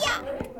gyatt - Botón de Efecto Sonoro